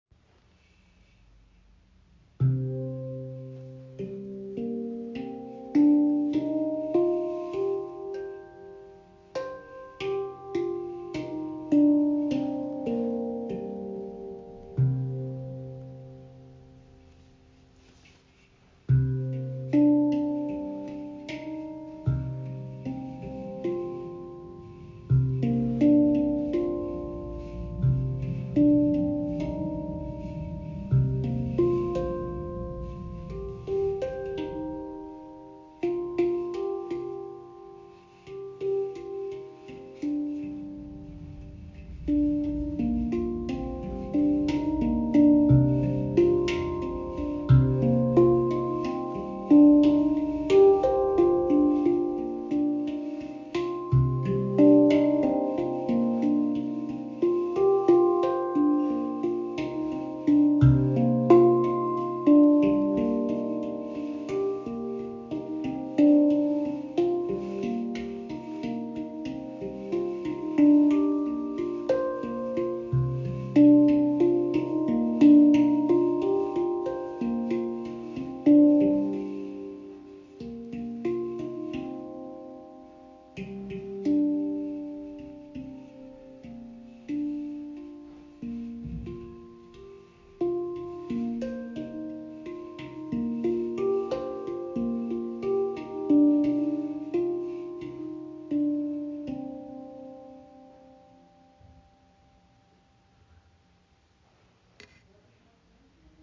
Weiche, tiefe Töne mit meditativer Ausstrahlung und spiritueller Tiefe.
• Icon Weicher Anschlag und langanhaltende Schwingung.
Die C Amara Stimmung klingt warm, tiefgründig und beruhigend.
Die C Amara Shaktipan Handpan bietet eine sanfte, mystische Klangfarbe mit meditativem Charakter – ideal für tiefes Eintauchen, Klangreisen und intuitive Improvisation.
Die C Amara Stimmung zeichnet sich durch ihren sanften, mollartigen Charakter und eine tiefe, warme Klangfarbe aus.